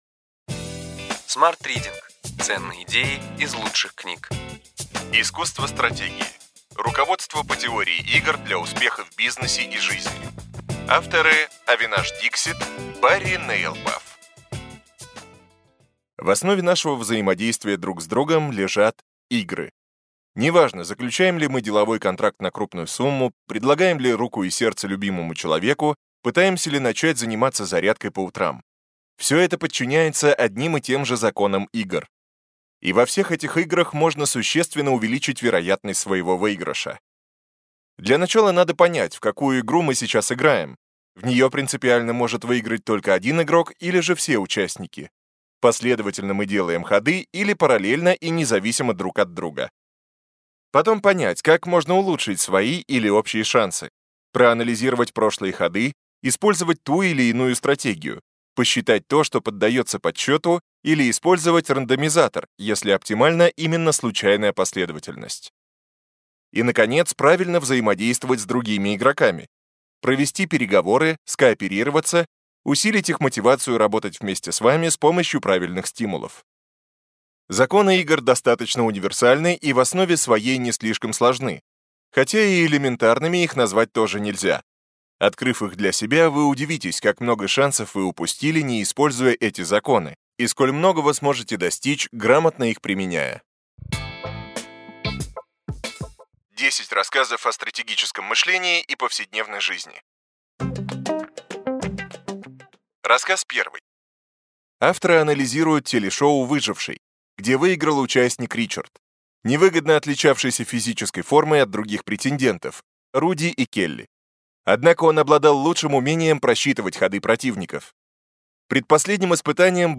Аудиокнига Ключевые идеи книги: Искусство стратегии: руководство по теории игр для успеха в бизнесе и жизни.